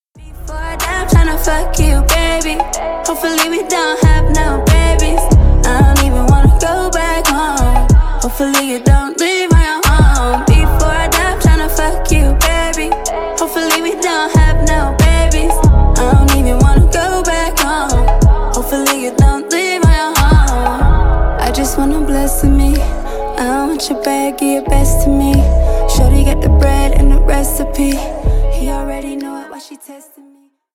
• Качество: 320, Stereo
женский вокал
Хип-хоп
спокойные